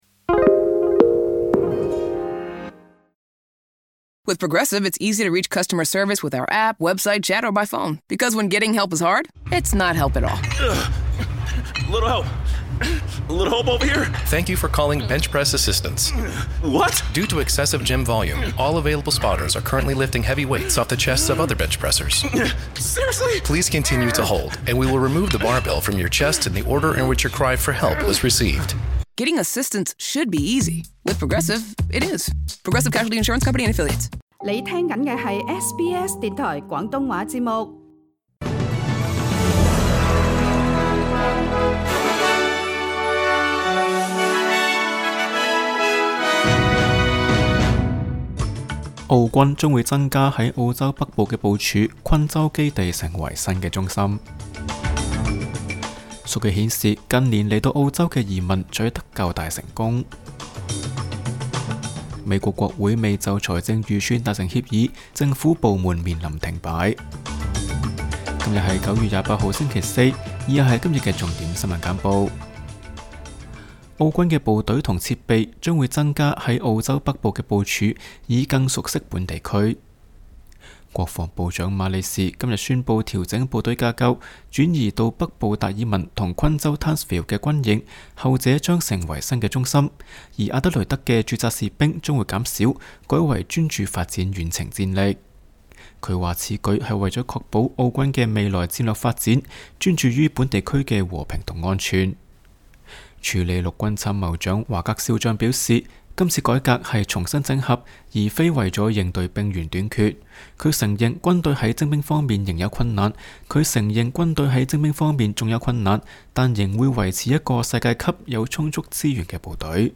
SBS 廣東話節目新聞簡報